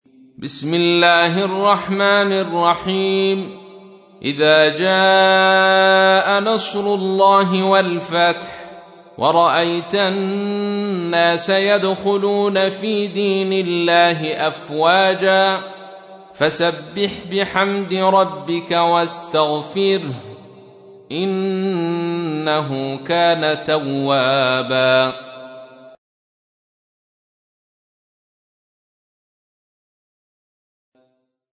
تحميل : 110. سورة النصر / القارئ عبد الرشيد صوفي / القرآن الكريم / موقع يا حسين